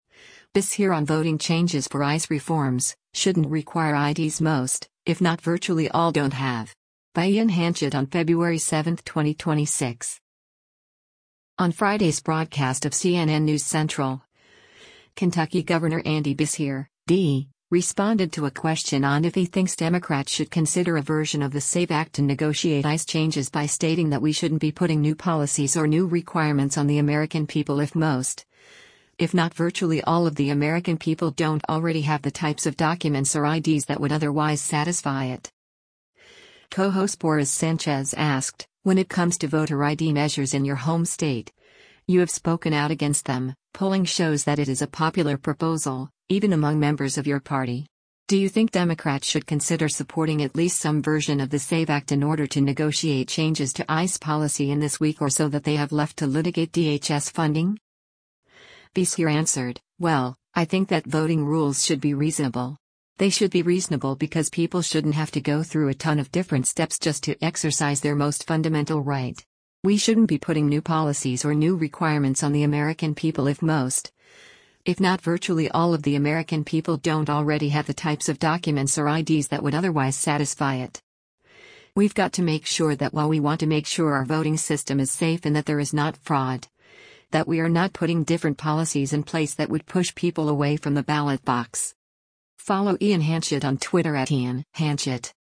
On Friday’s broadcast of “CNN News Central,” Kentucky Gov. Andy Beshear (D) responded to a question on if he thinks Democrats should consider a version of the SAVE Act to negotiate ICE changes by stating that “We shouldn’t be putting new policies or new requirements on the American people if most, if not virtually all of the American people don’t already have the types of documents or IDs that would otherwise satisfy it.”